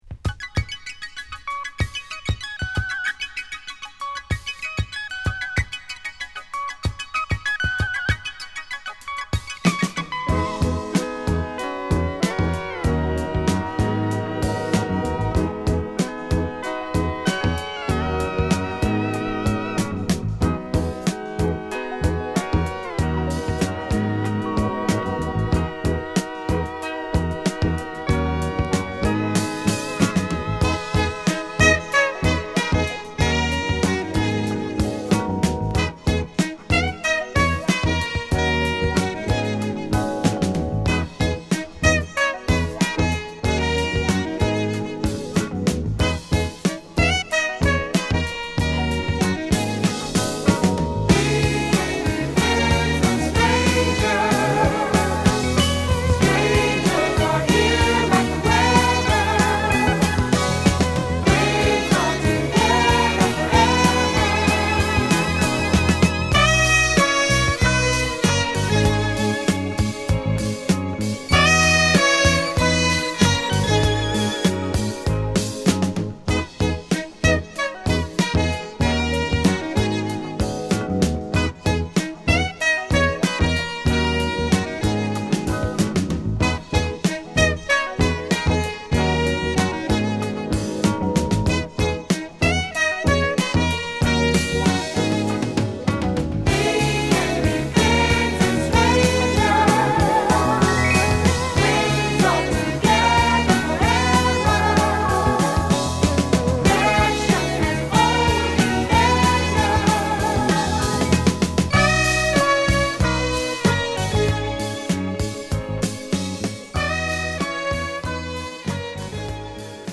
テキサス出身のSax奏者
ちょっぴり哀愁漂うSaxが印象的なメロウジャズフュージョン！